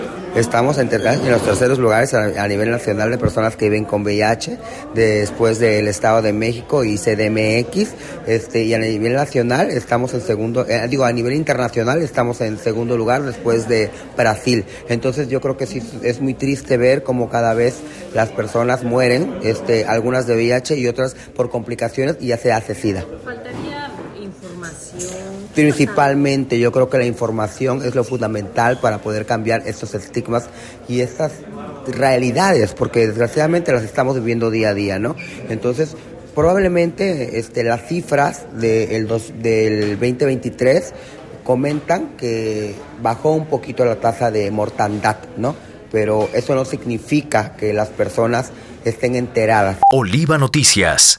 En entrevista, precisó que la falta de información y la realización de pruebas de detección es uno de los principales obstáculos que enfrentan las amas de casa para prevenir y tratar el VIH/SIDA.